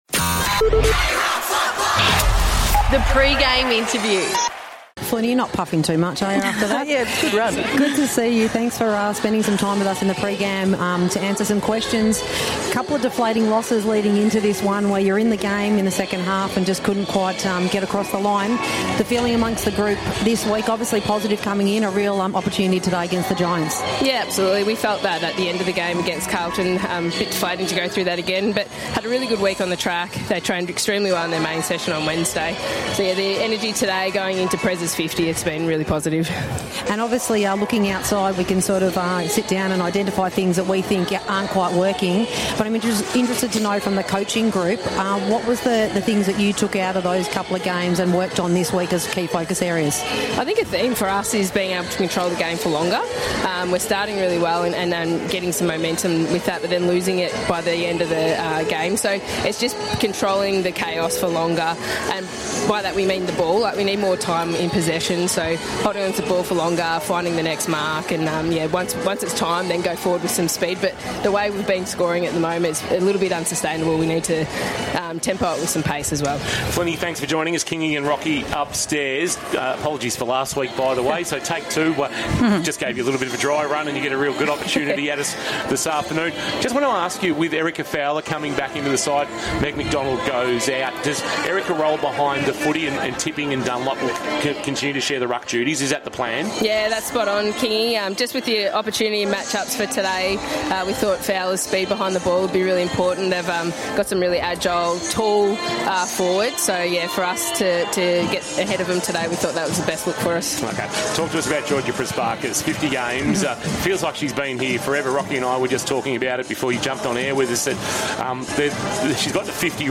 2025 - AFLW - Round 9 - Geelong vs. GWS Giants - Pre-match interview